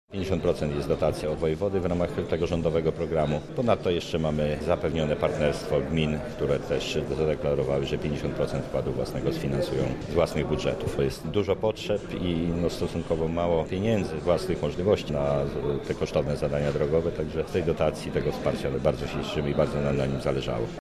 O szczegółach mówi Paweł Pikula – starosta lubelski